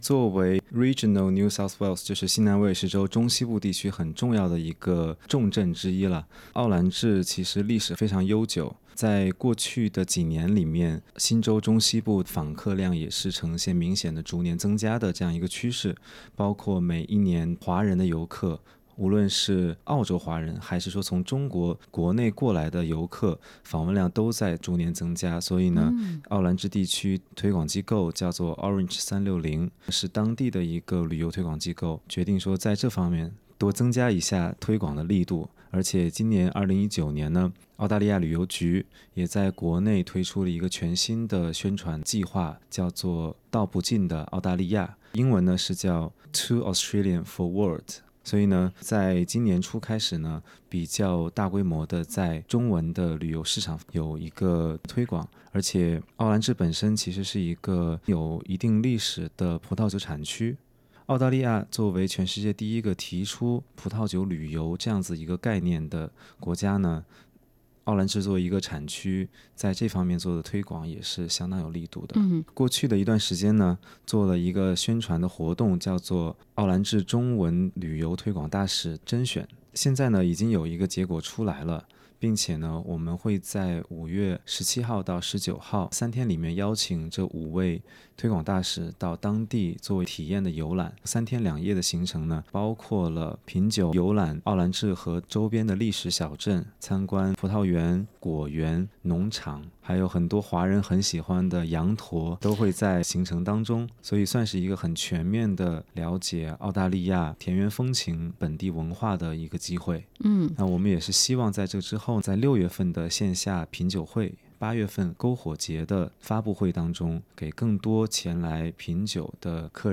SBS Mandarin